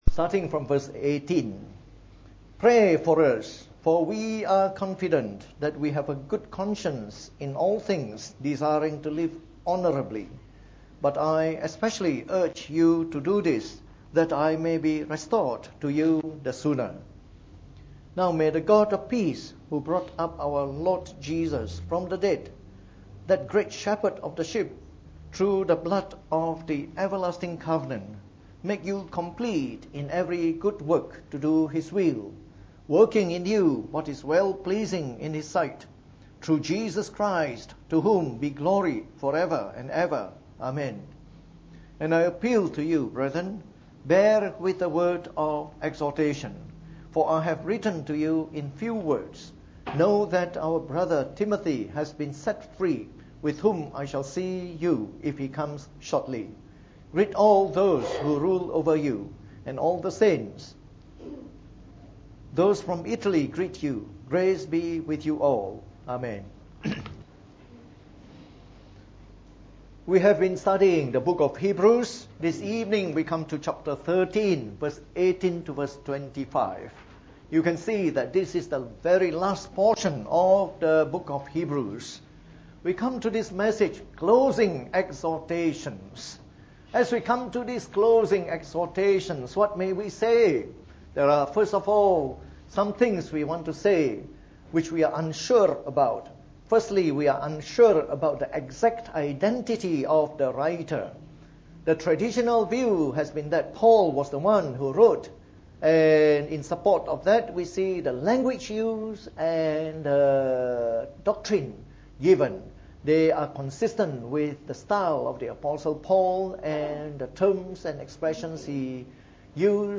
Preached on the 8th of November 2015. From our series on the “Epistle to the Hebrews” delivered in the Evening Service.